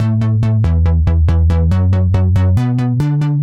Index of /musicradar/french-house-chillout-samples/140bpm/Instruments
FHC_NippaBass_140-A.wav